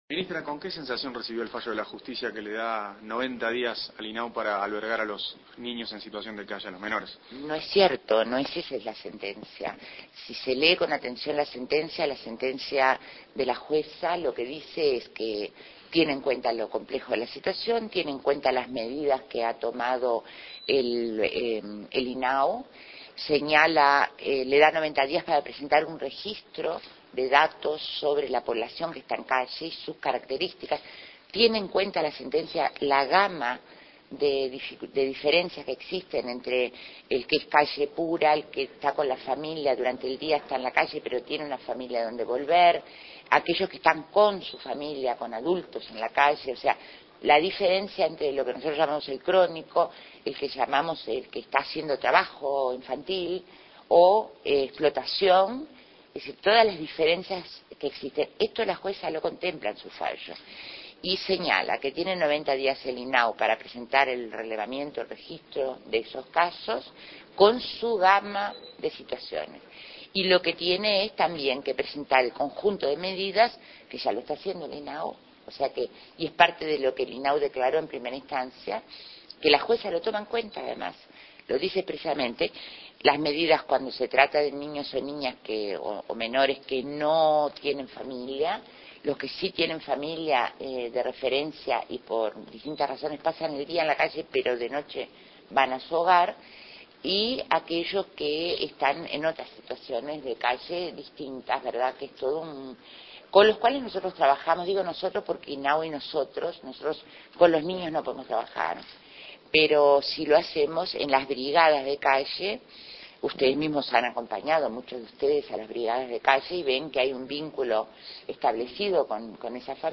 Declaraciones a la prensa de la Ministra de Desarrollo Social; Marina Arismendi, a la salida del CEP.